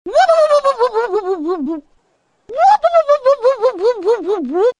• Качество: 320, Stereo
смешные
голосовые